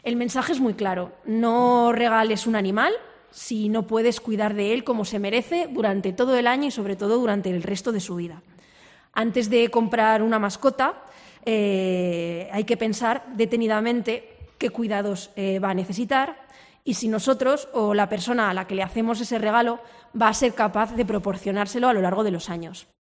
La consejera de Medio Ambiente explica el objetivo de la campaña 'Un animal no es un juguete'